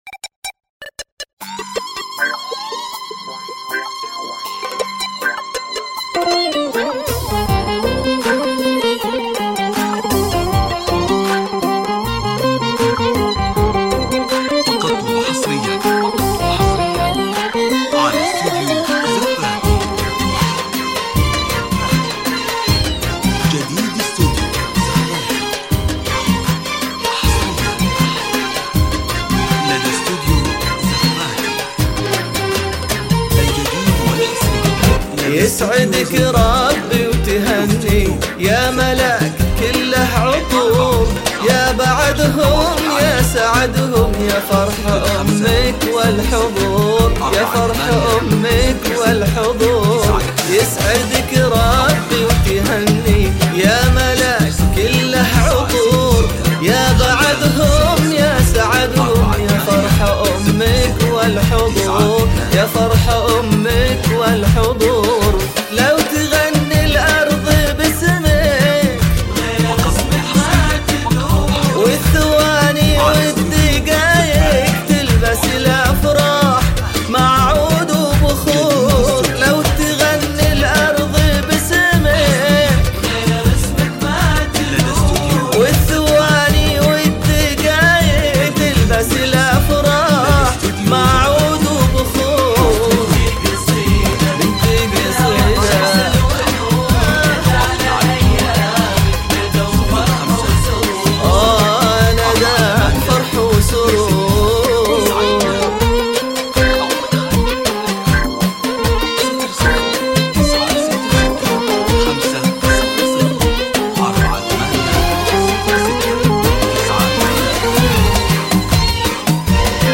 زفة
بدون موسيقى